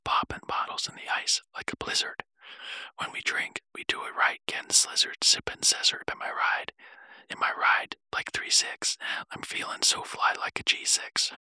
audio-watermarking emotional-tts expressive-tts text-to-speech voice-synthesis watermarked-audio
Generate expressive, natural speech with Resemble AI's Chatterbox.
"pitch": "medium",
"voice": "William (Whispering)",